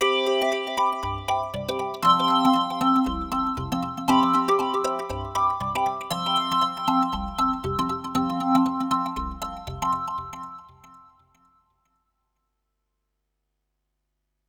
Cortinilla musical alegre
Sonidos: Música